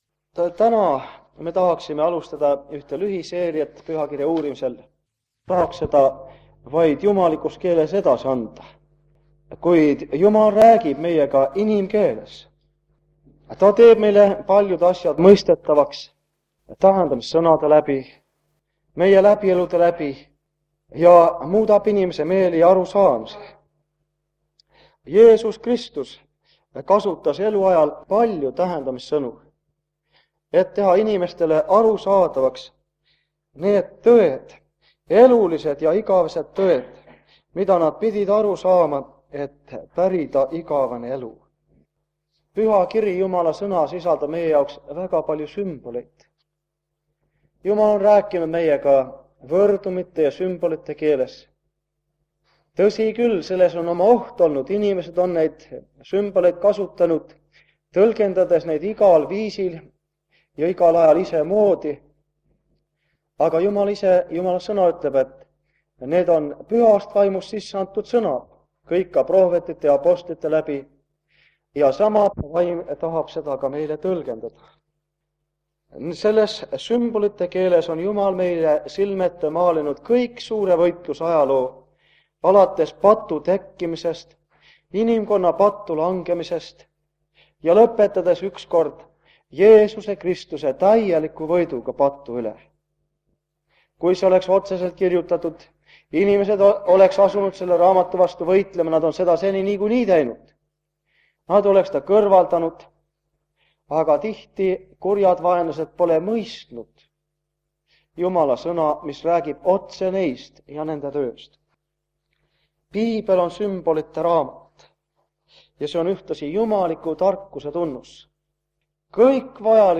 Jutluste minisari Kingissepa adventkoguduses 1978 aastal.